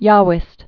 (yäwĭst) also Yah·vist (-vĭst)